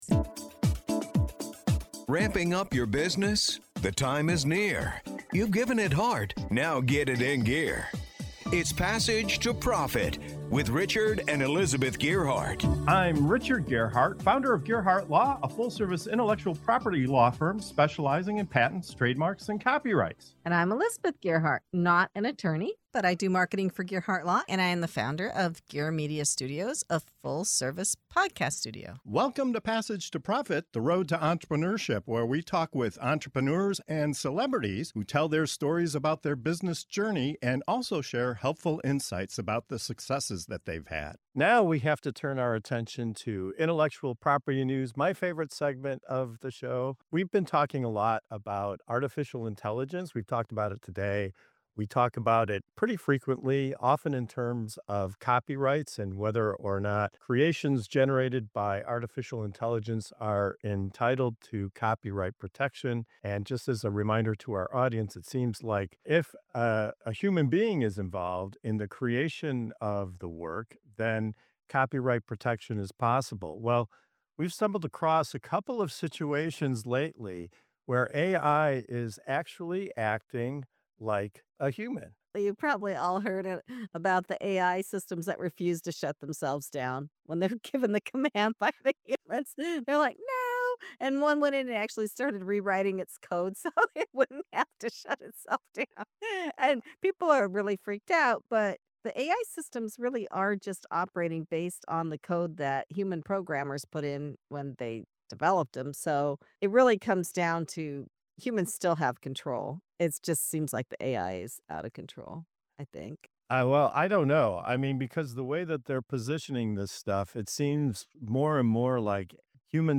Tune in as our panel explores the legal, ethical, and downright bizarre implications of AI's rapid evolution.